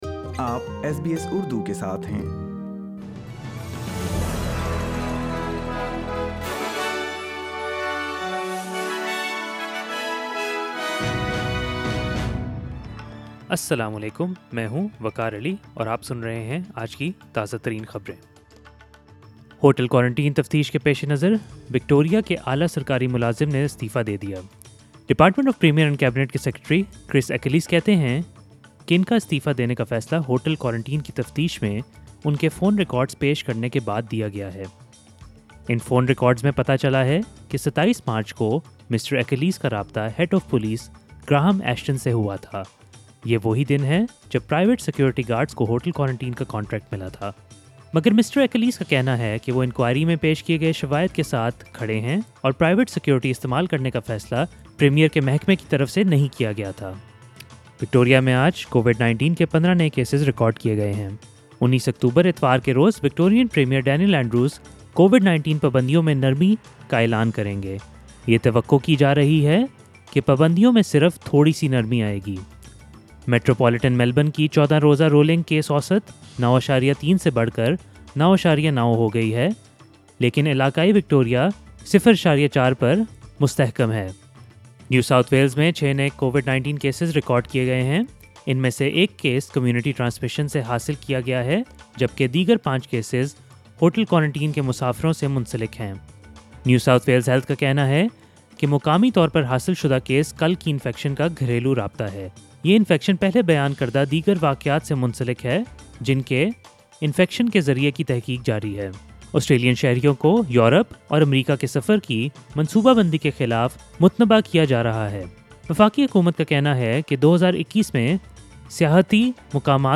ایس بی ایس اردو خبریں 12 اکتوبر 2020